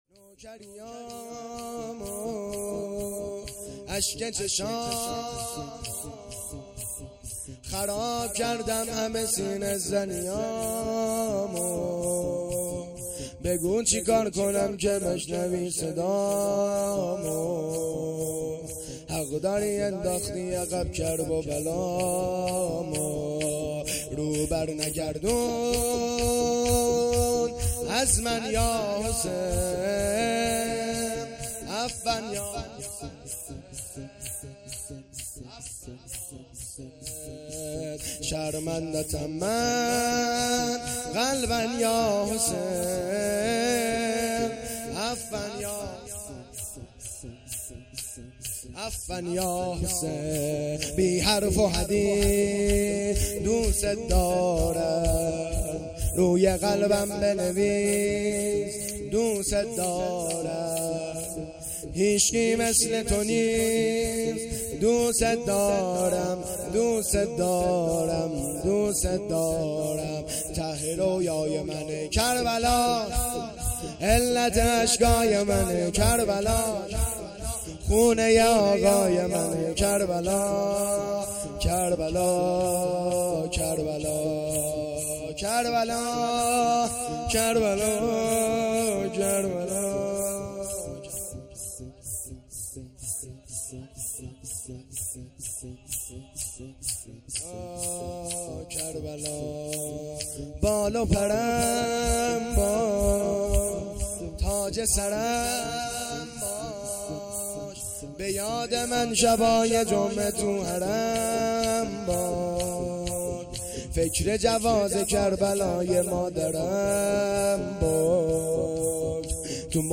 شورپایانی
شب شهادت امام حسن مجتبی(ع)- سال ۱۴۰۰